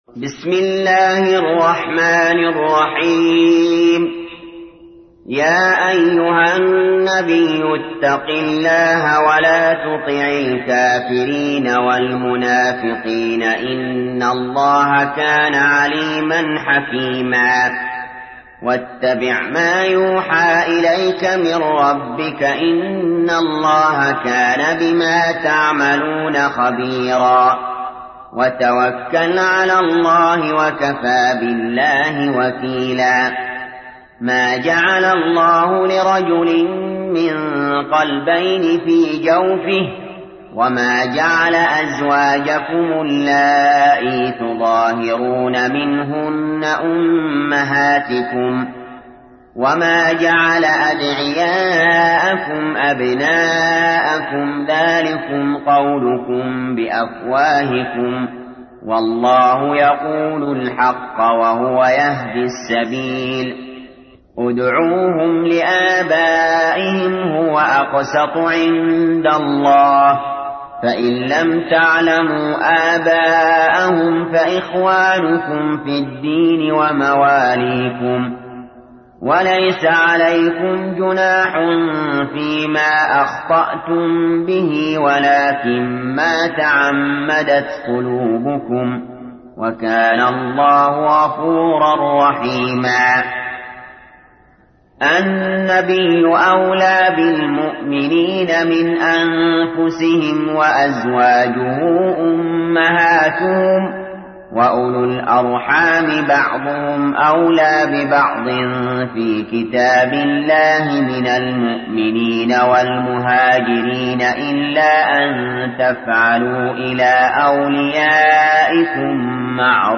تحميل : 33. سورة الأحزاب / القارئ علي جابر / القرآن الكريم / موقع يا حسين